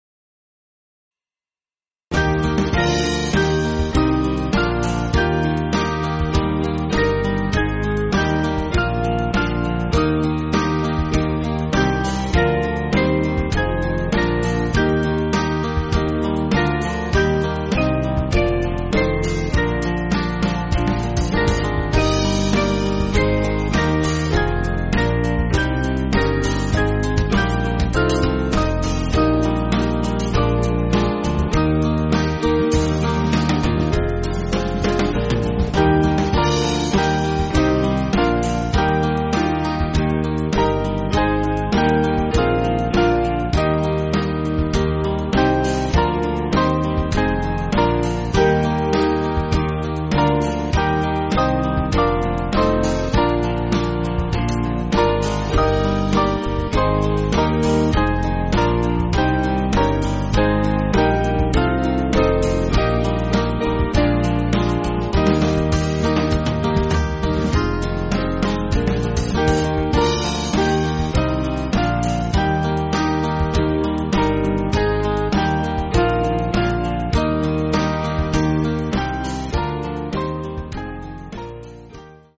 Small Band
(CM)   6/Eb